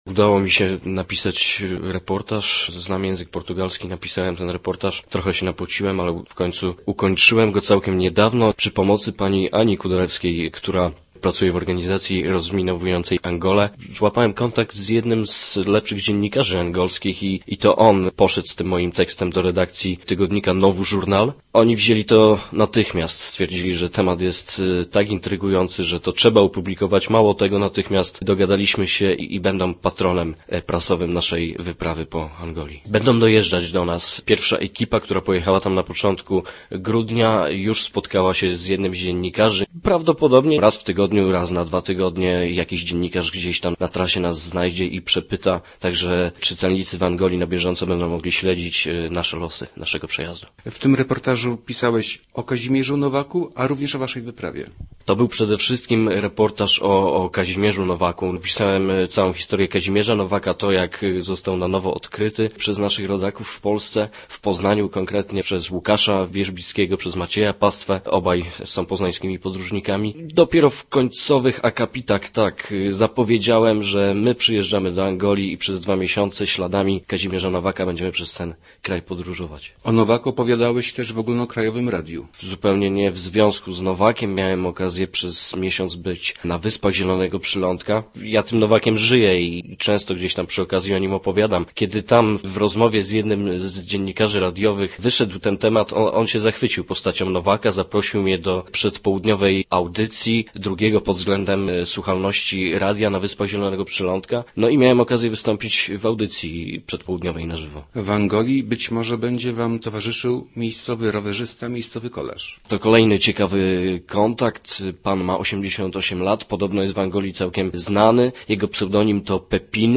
8cgij3agw05wiy4_reportaz_o_nowaku.mp3